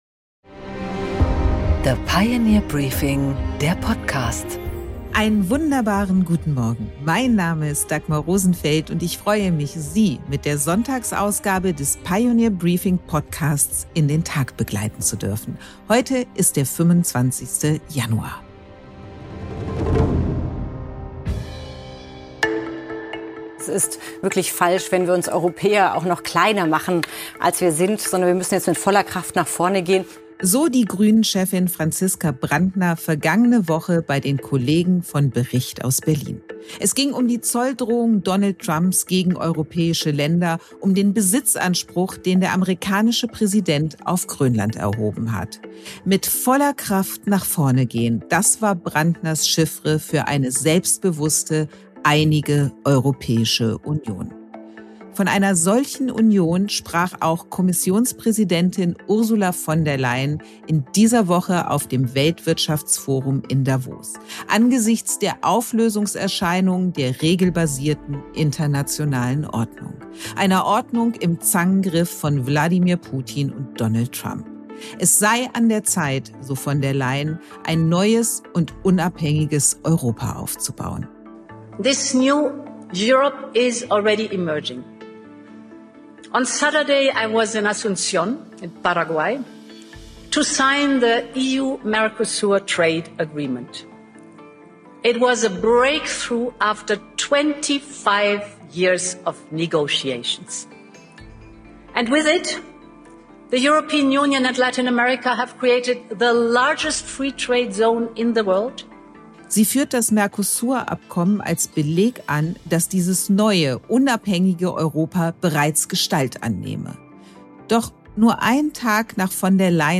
Dagmar Rosenfeld präsentiert die Pioneer Briefing Weekend Edition.